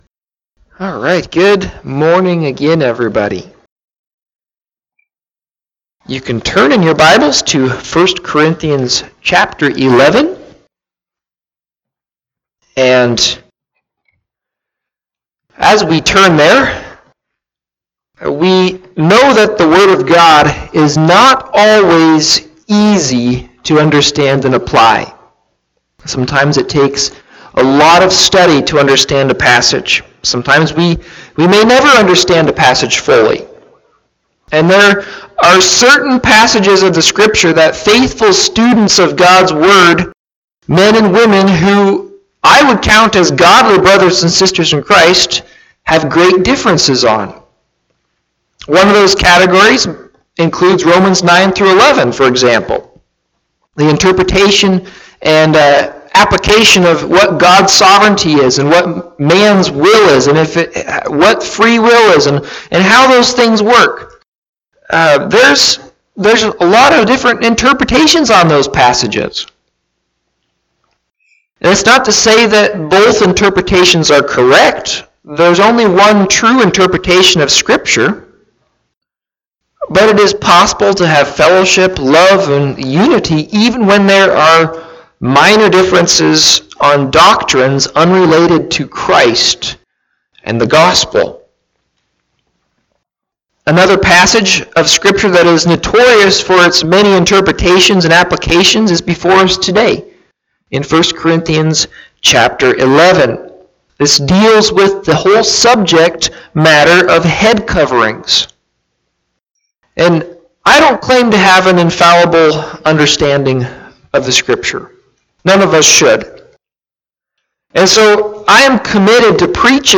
Listen to Audio of the sermon or Click Facebook live link above.
Service Type: Morning Sevice